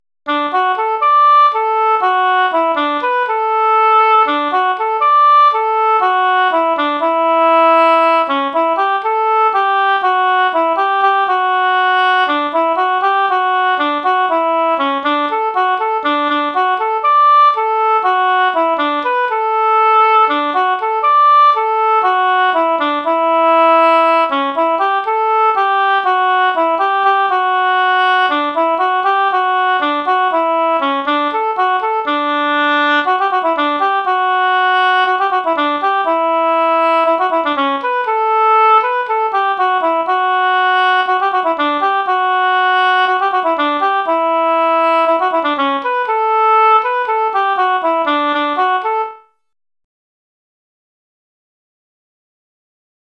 GAITA MUSIC ARCHIVE